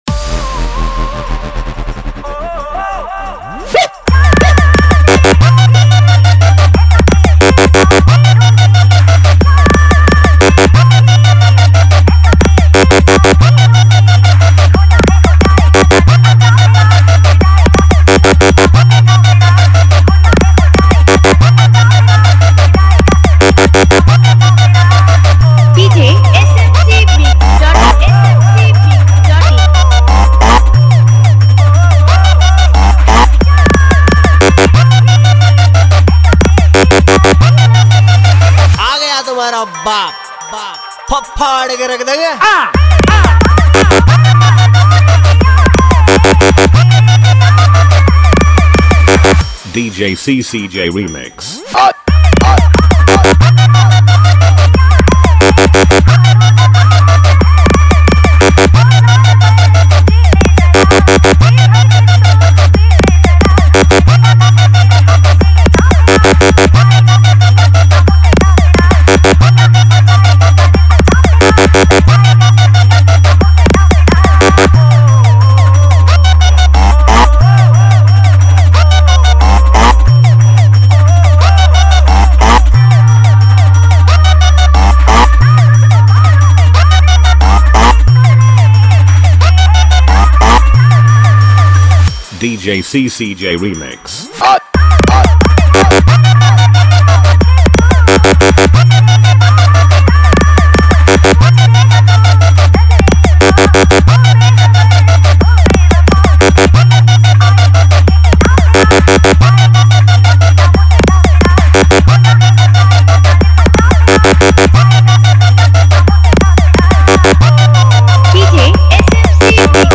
Competition Mix